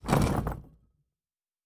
Stone 15.wav